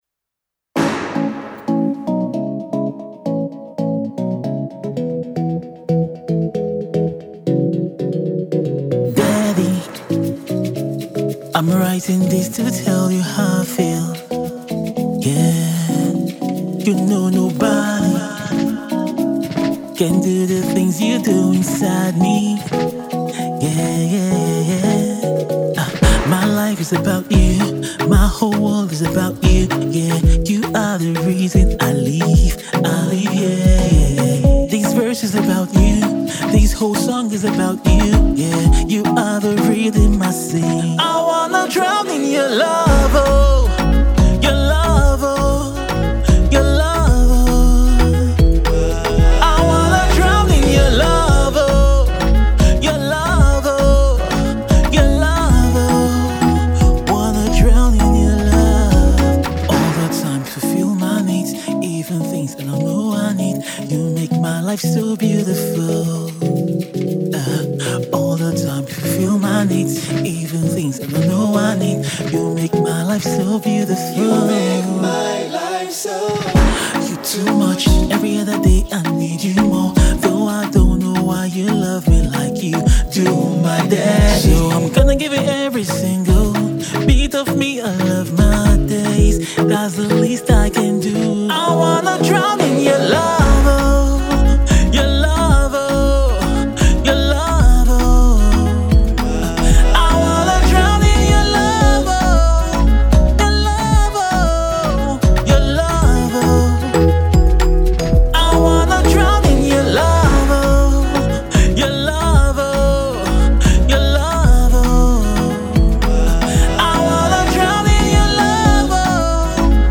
Christian Afro pop single